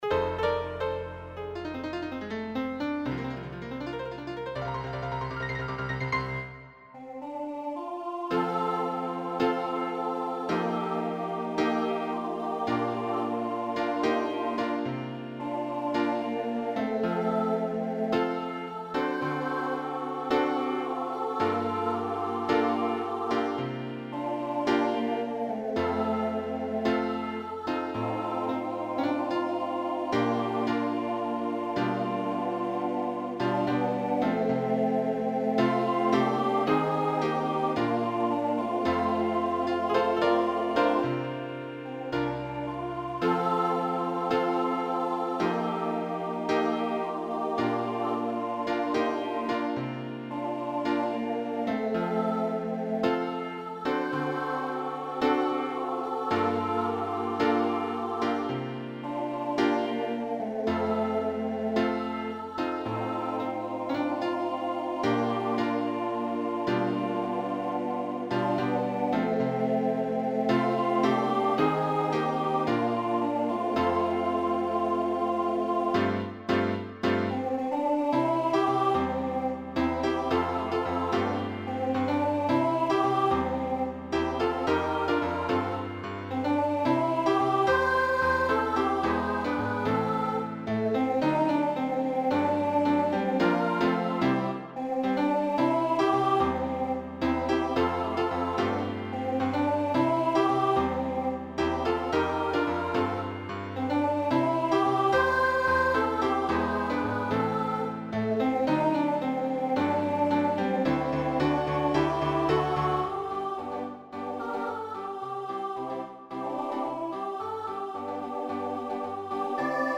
A fun, lively gospel song to shake away the blues!
this piece is for SSA and piano.
Soloist, SSA with piano